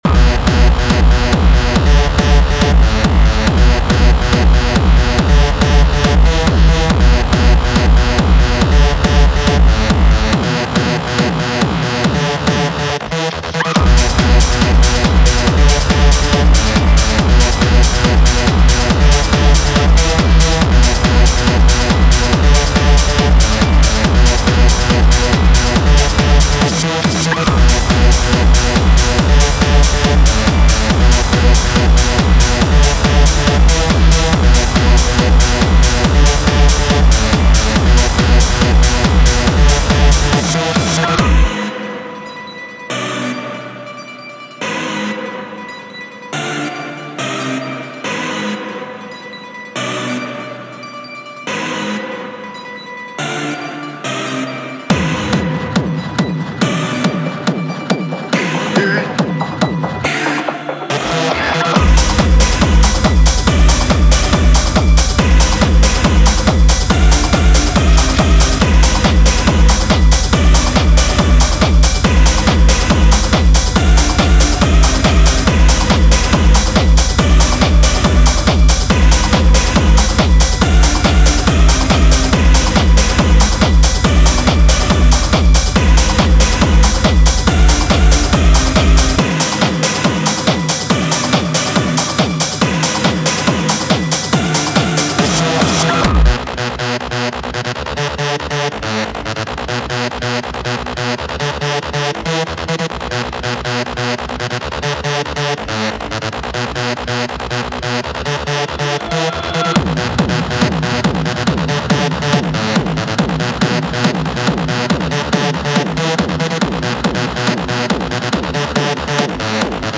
Styl: Techno, Hardtek/Hardcore